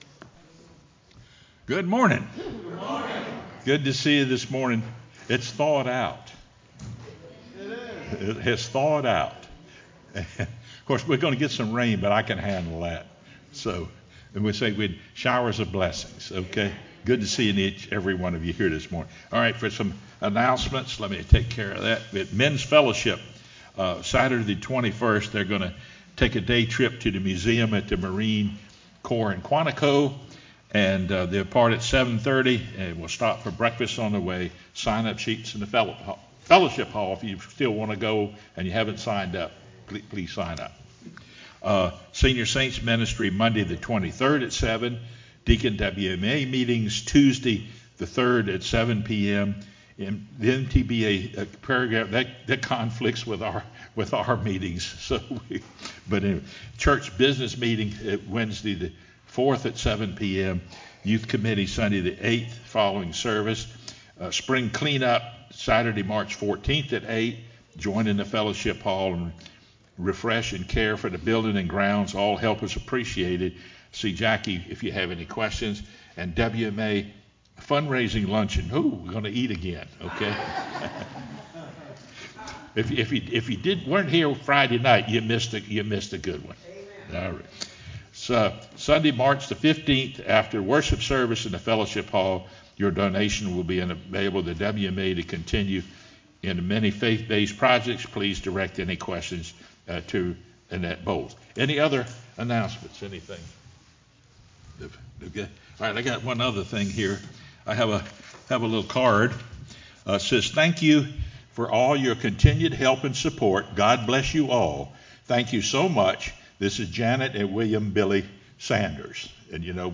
sermonFeb15-CD.mp3